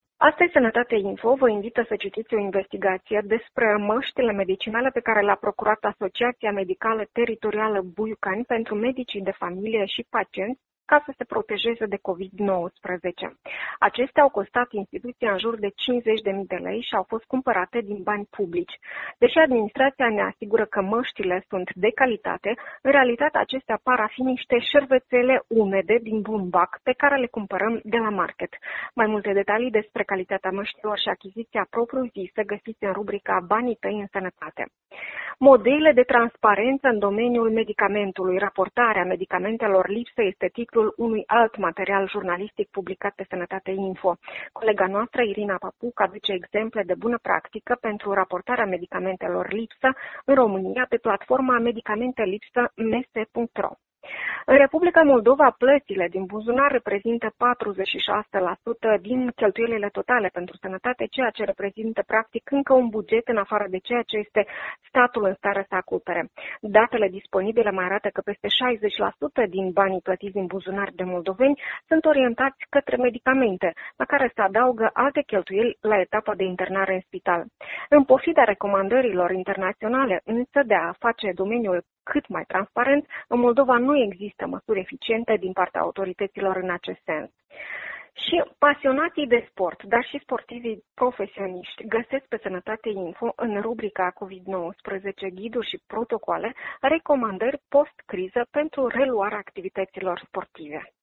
de Europa Liberă Moldova